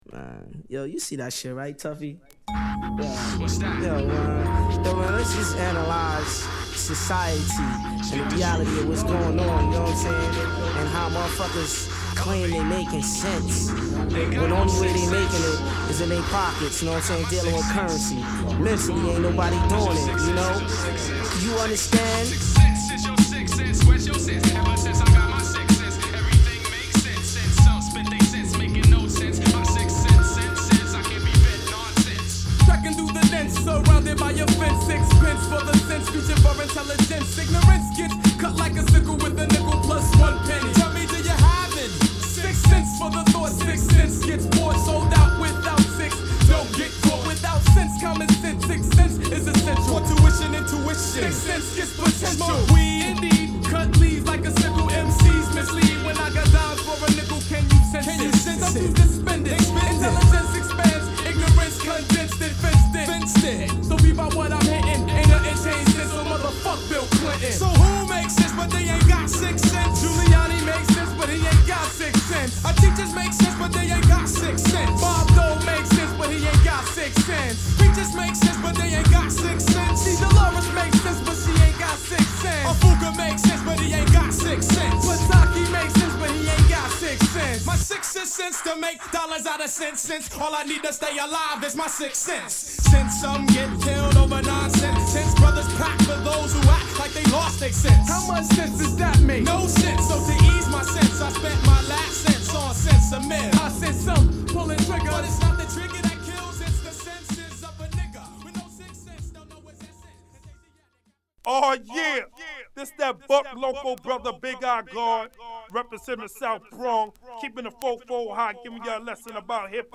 Dirty Mix